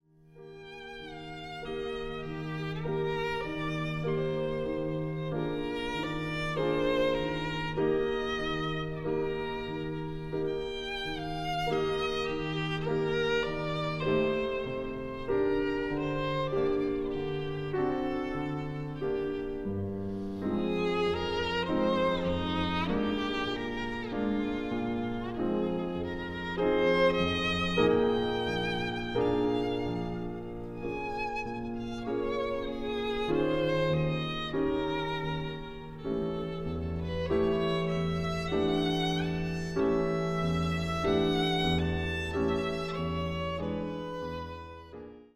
ヴァイオリン
ピアノ
録音：2023年3月　テンペリアウキオ教会（ヘルシンキ）